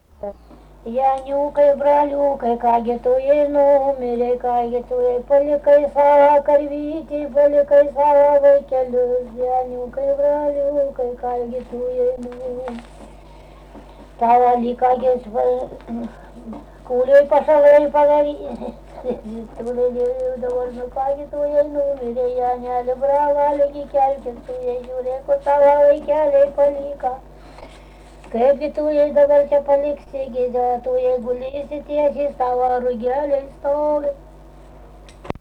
rauda
Rageliai
vokalinis